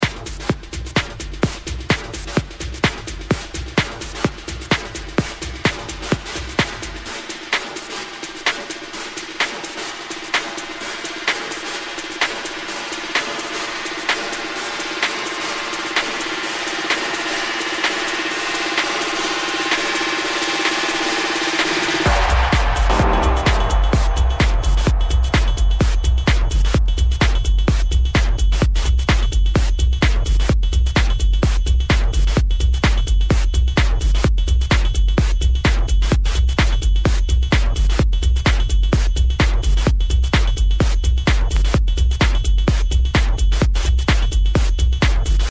techno bomb